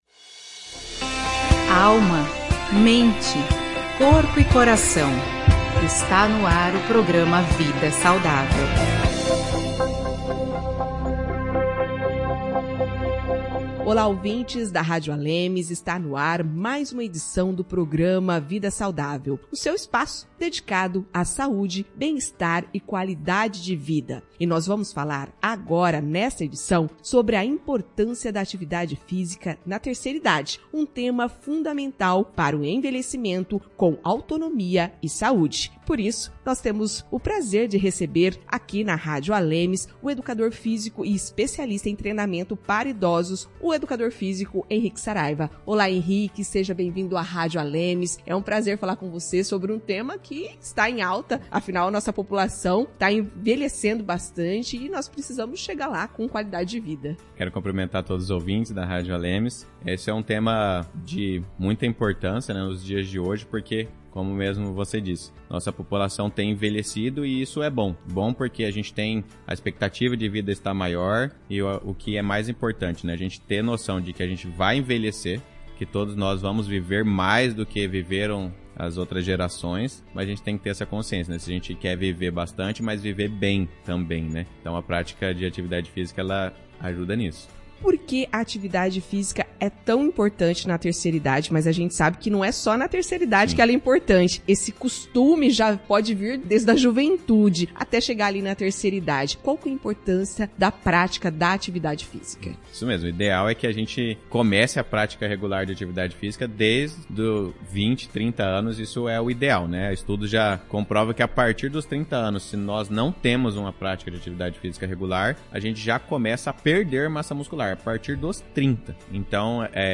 Em entrevista ao programa Vida Saudável